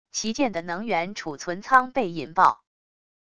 旗舰的能源储存仓被引爆wav音频